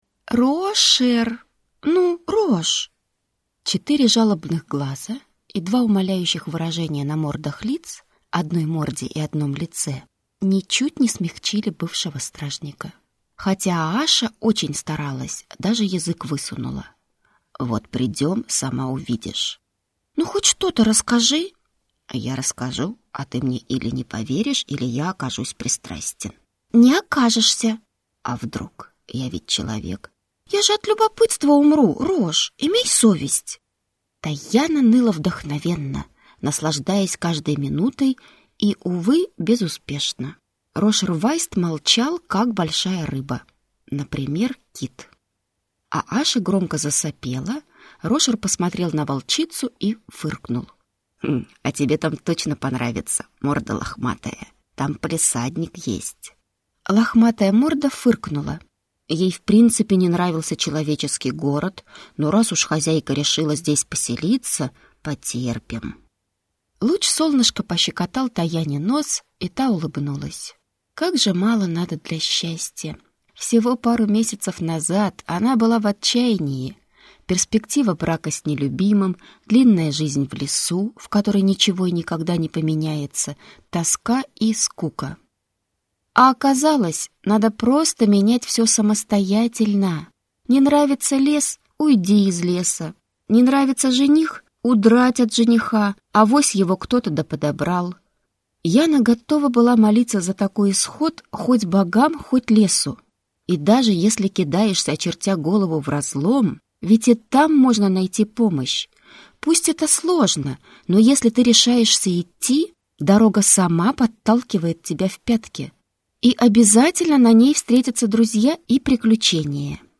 Аудиокнига Тайяна. Влюбиться в небо | Библиотека аудиокниг